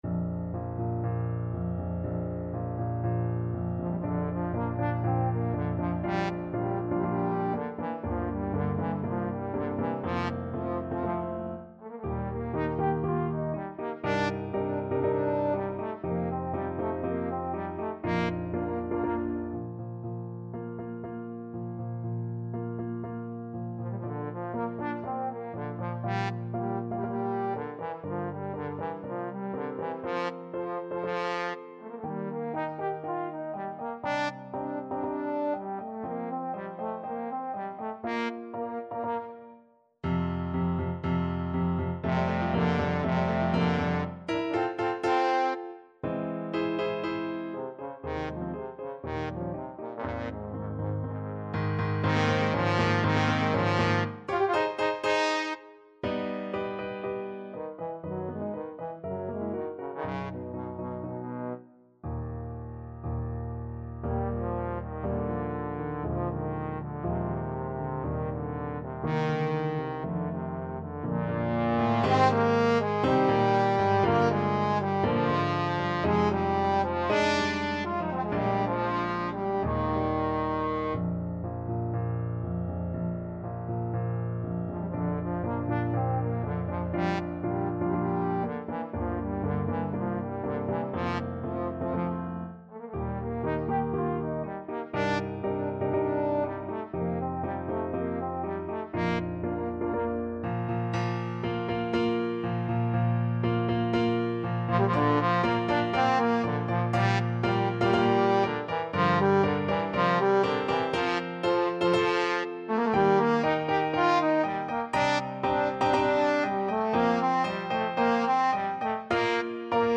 Trombone
4/4 (View more 4/4 Music)
~ = 120 Tempo di Marcia un poco vivace
Bb major (Sounding Pitch) (View more Bb major Music for Trombone )
Classical (View more Classical Trombone Music)